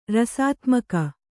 ♪ rasātmaka